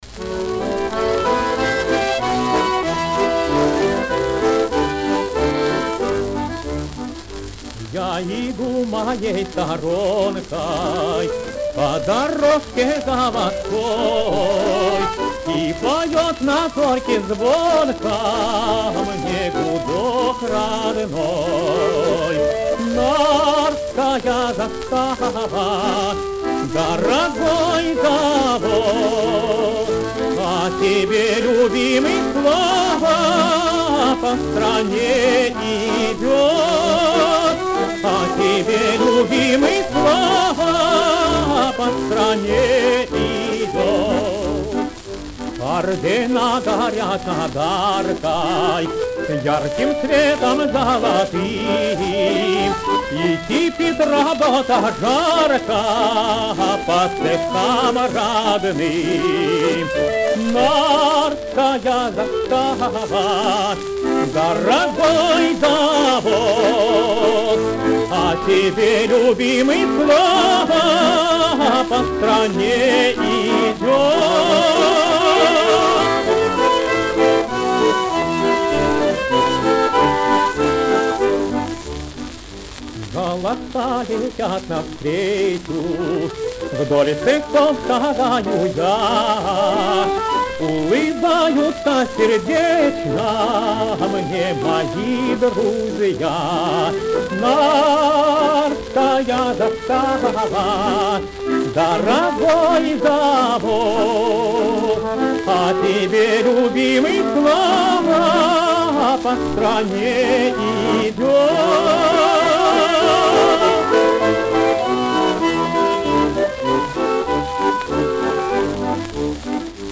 Повышаем качество, а то на сайте совсем уж убийственное.
дуэт баянистов